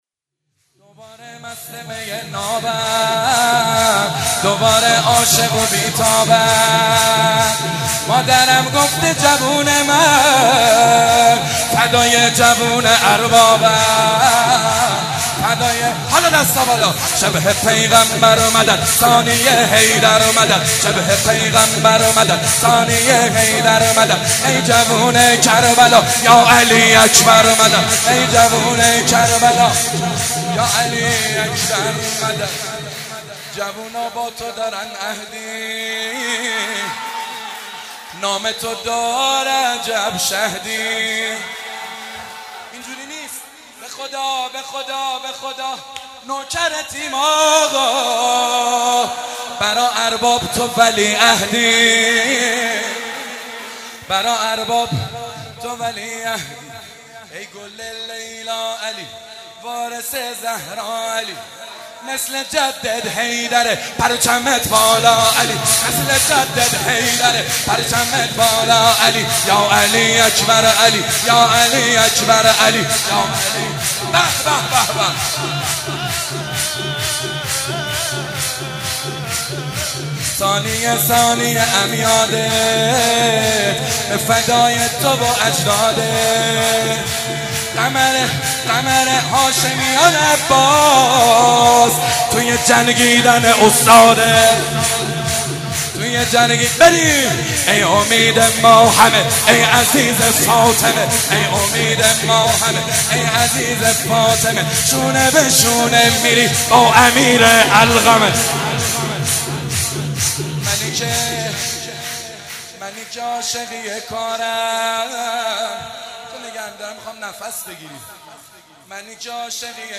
(سرود،ذکر)